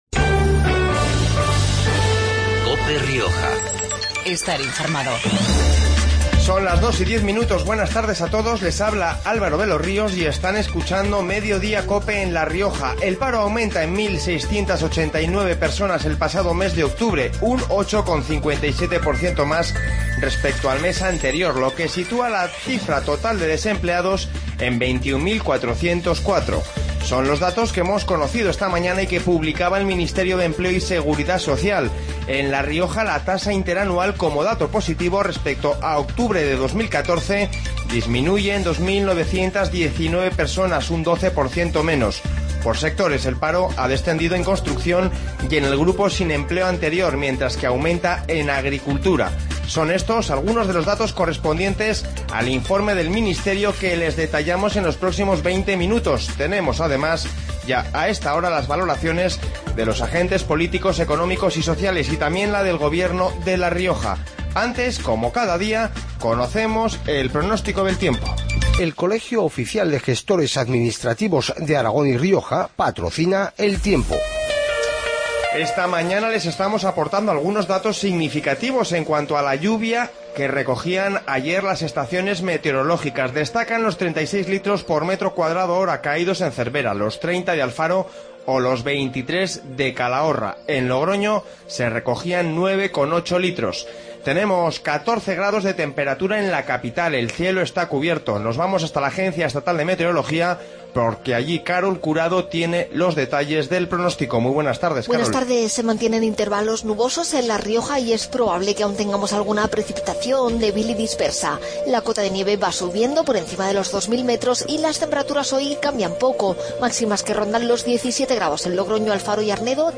Informativo Mediodia en La Rioja 03-11-15